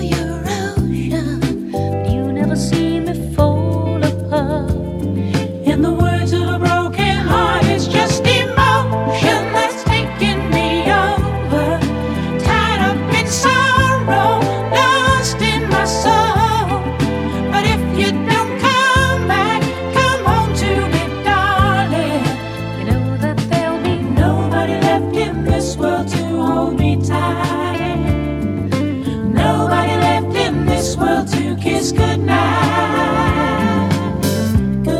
# Disco